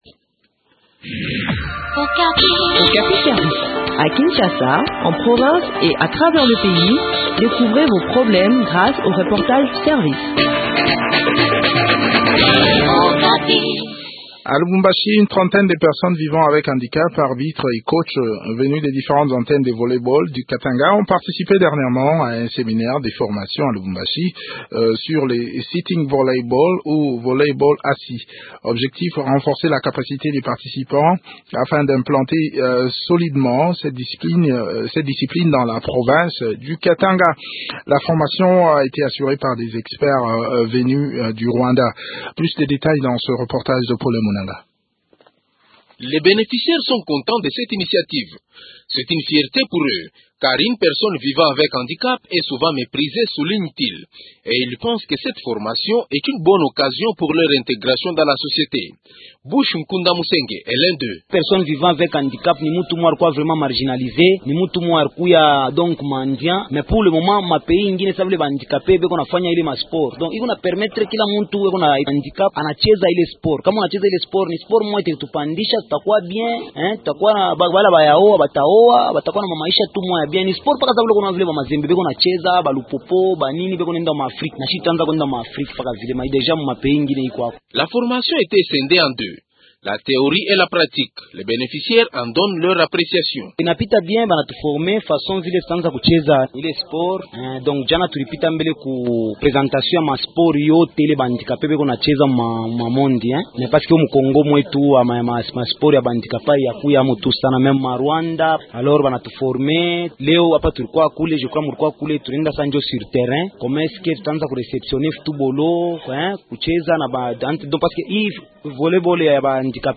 Le point sur le déroulement de ce séminaire dans cet entretien